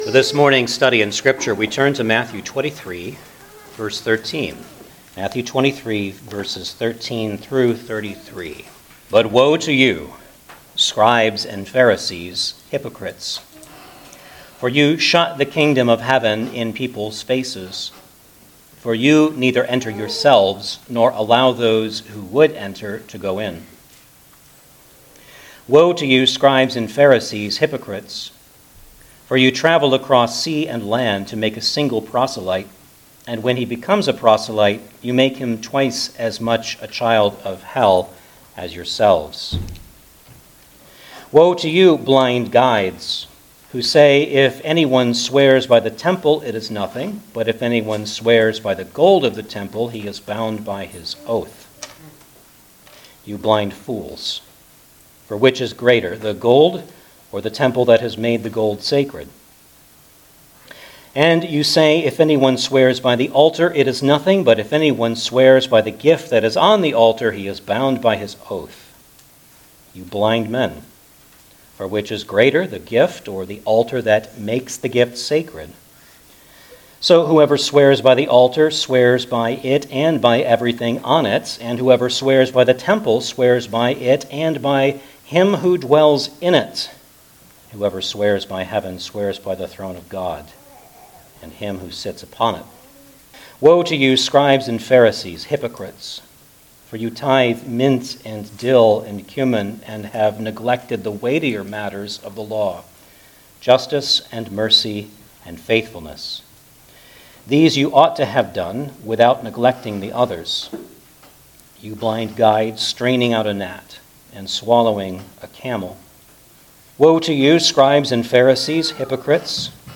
Matthew 23:13-33 Service Type: Sunday Morning Service Download the order of worship here .